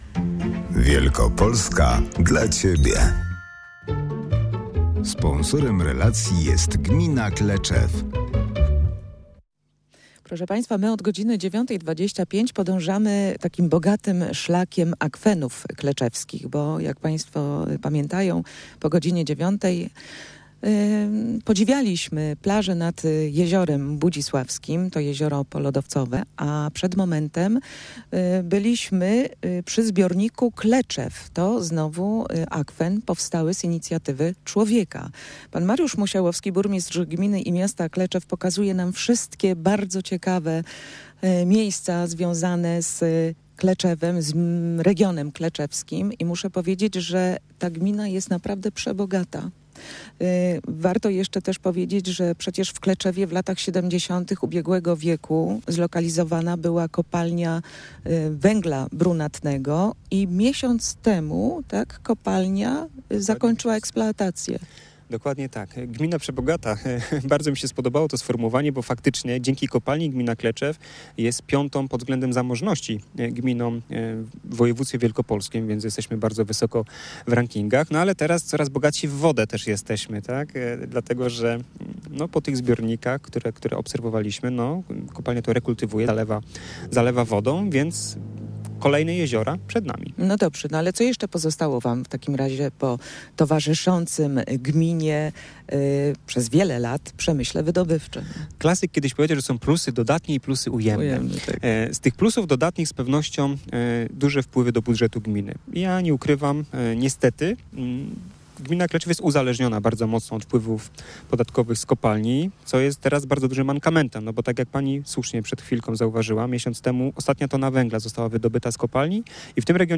Dziś zawitaliśmy do gminy Kleczew we wschodniej Wielkopolsce.
Byliśmy też w miejscu ,gdzie jeszcze miesiąc temu pracowały ciężkie maszyny przy wydobyciu węgla.